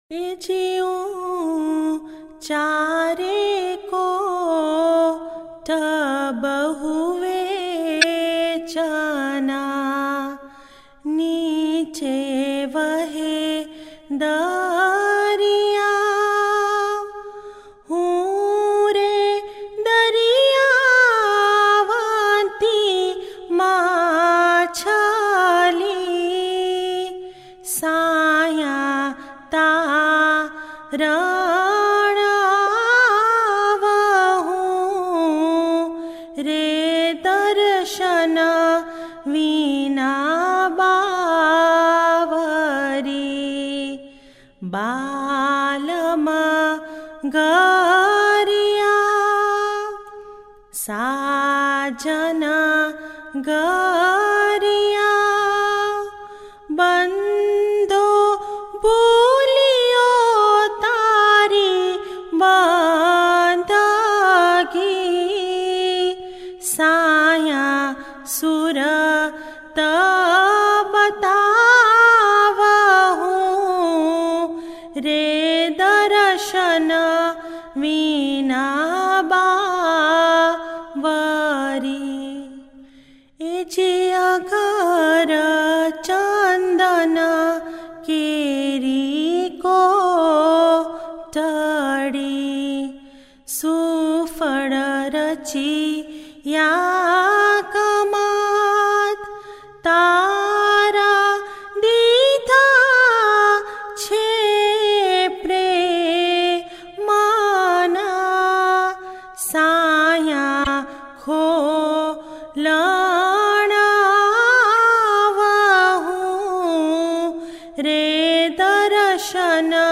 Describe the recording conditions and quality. We are presenting four different renditions from various parts of the world.